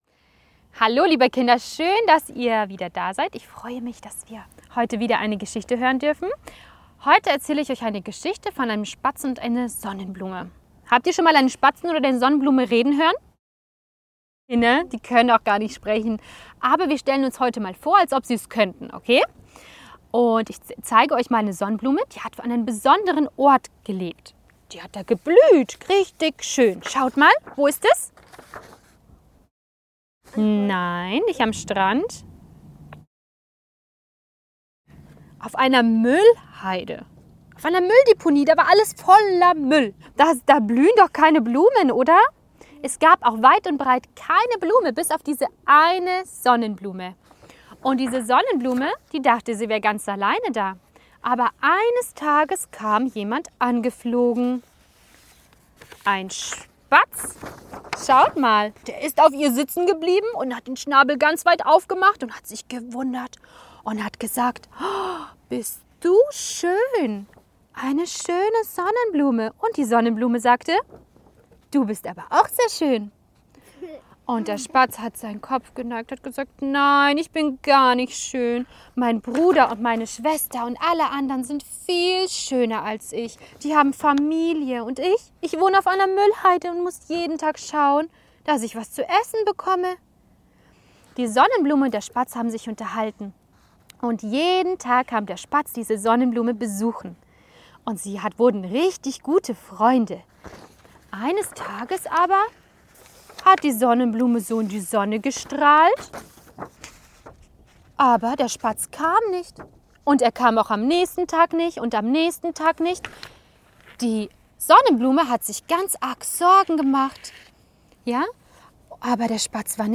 Wahre Kurzgeschichten für Kinder